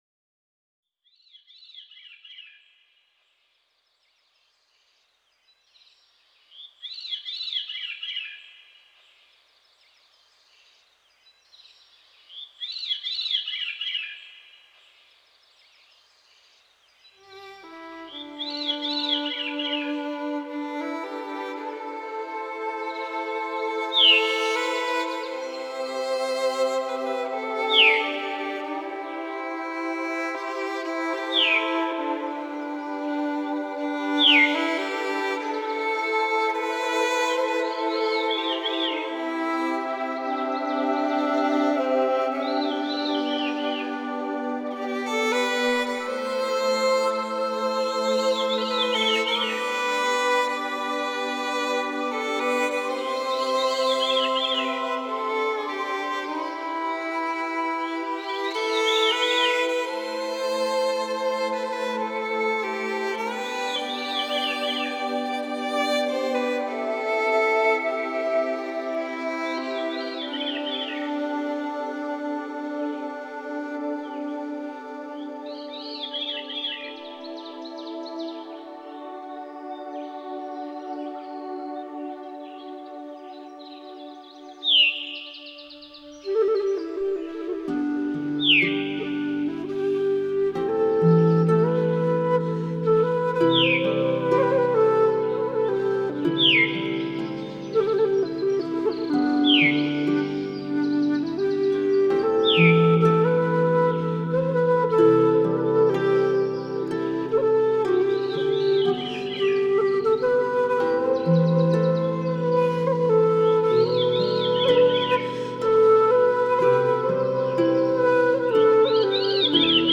冥想类音乐4
冥想音乐能帮助我们放松身心，还能减轻压力，集中注意力......